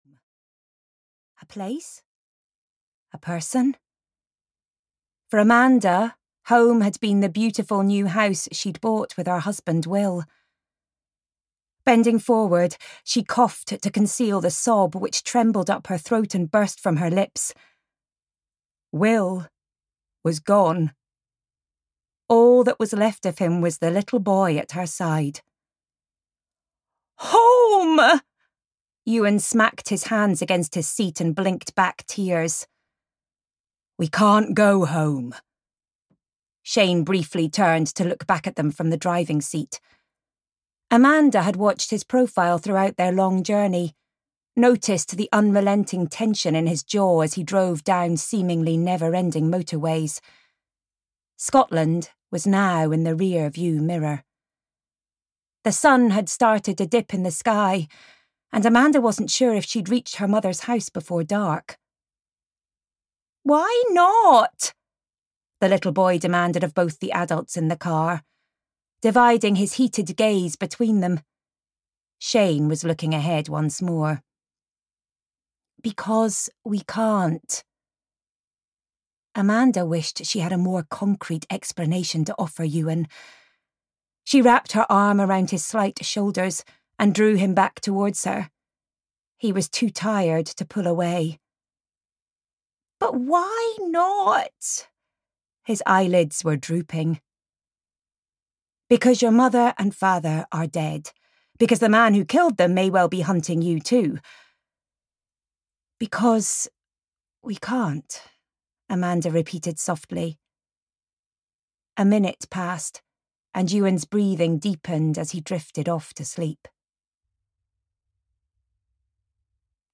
Last Witness (EN) audiokniha
Ukázka z knihy